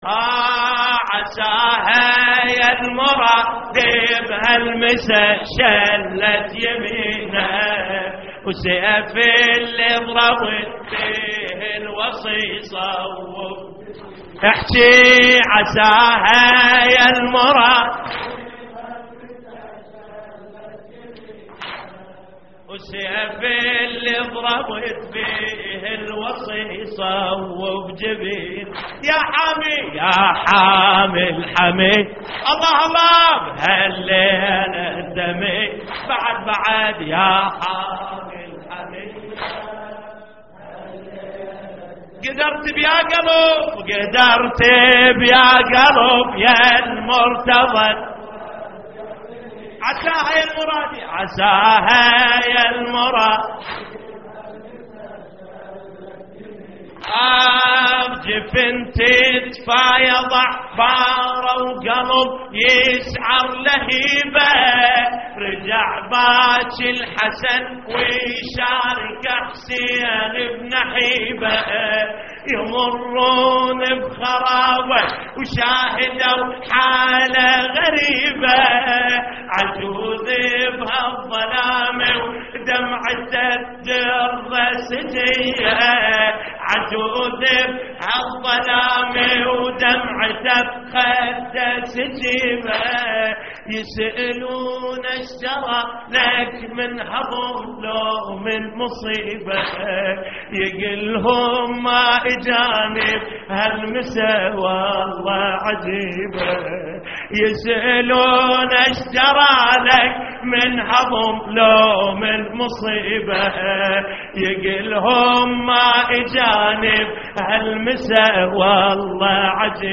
تحميل : عساها يالمرادي بهالمسا انشلت يمينك / مجموعة من الرواديد / اللطميات الحسينية / موقع يا حسين